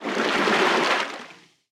Sfx_creature_penguin_swim_glide_02.ogg